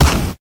snd_fireball.ogg